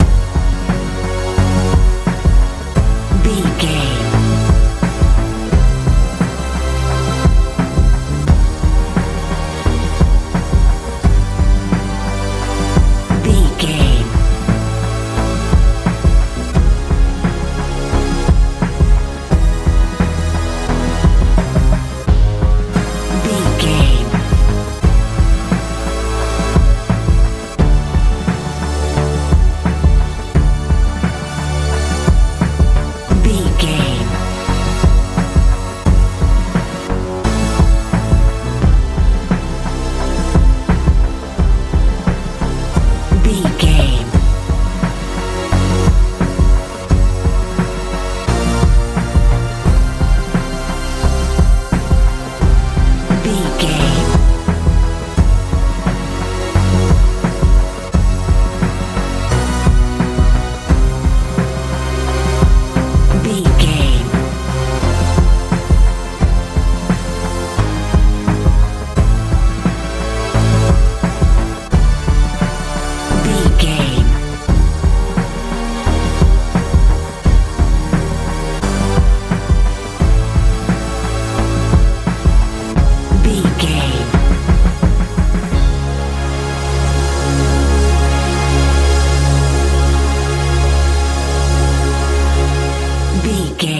alt hip hop feel
Ionian/Major
F♯
groovy
funky
synthesiser
bass guitar
drums
80s
90s
suspense
strange
heavy